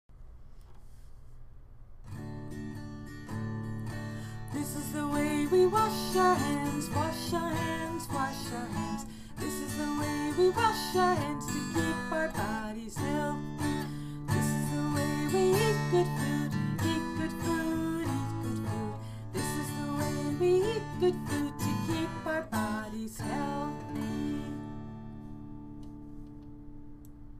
Tune: “Here We Go Round the Mulberry Bush”